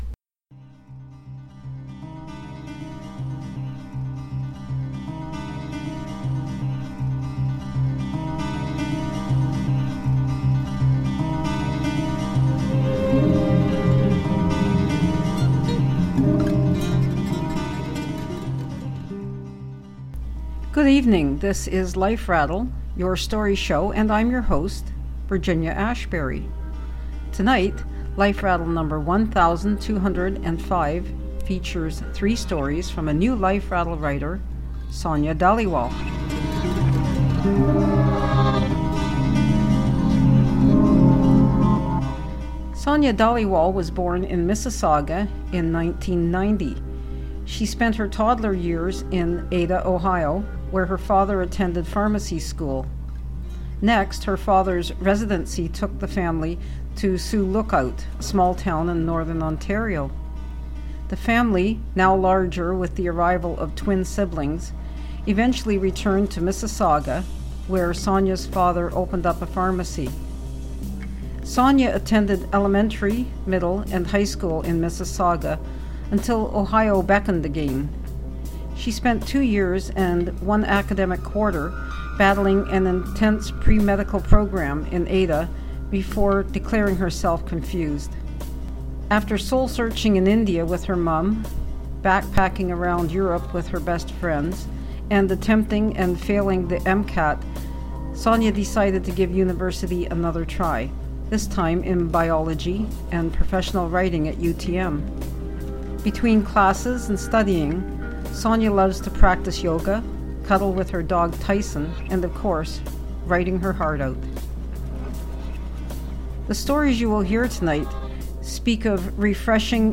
Tonight's stories contain situations and language that some listeners may find offensive.